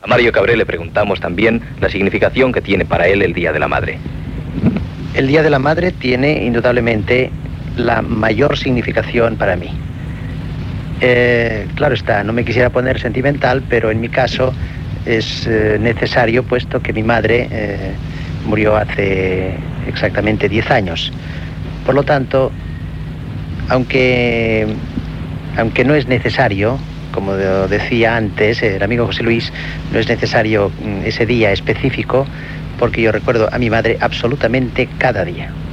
L'actor, escriptor i torero Mario Cabré parla del Dia de la Mare.
Extret de Crònica Sentimental de Ràdio Barcelona emesa el dia 29 d'octubre de 1994.